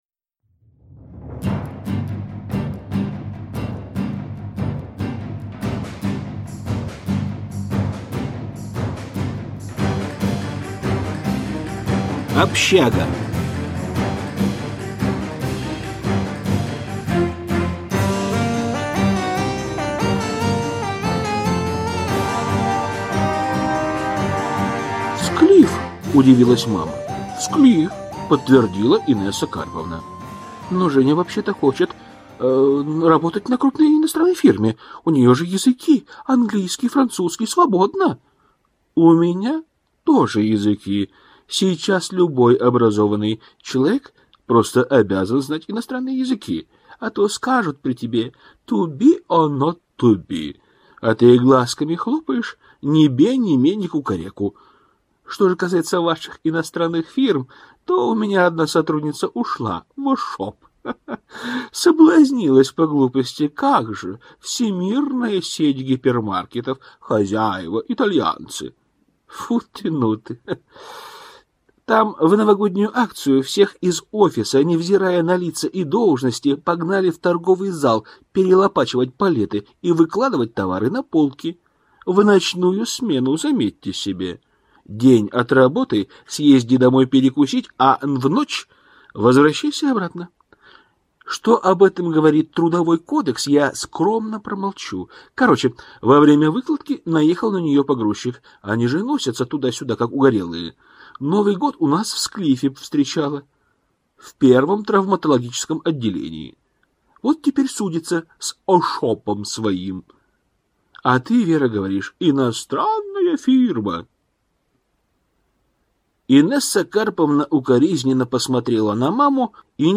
Аудиокнига Склиф. Скорая помощь | Библиотека аудиокниг